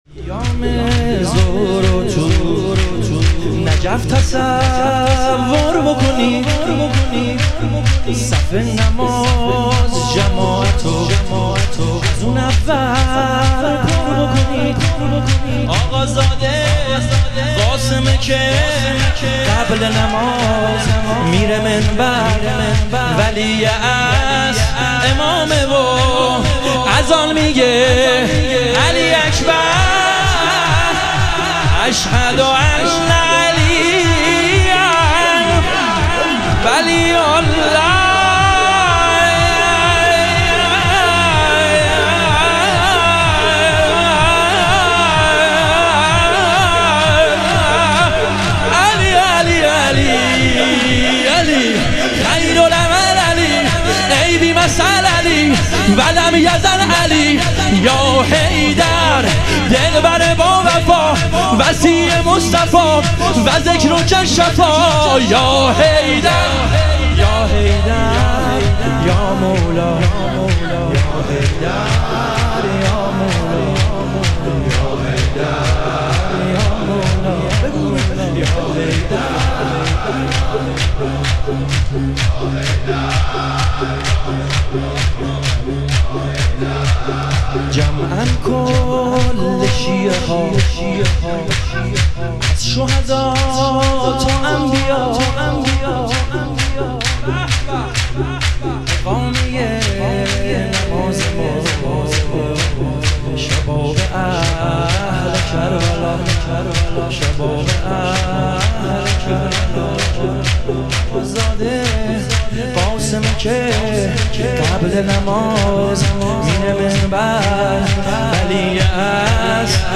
شهادت امام کاظم علیه السلام - شور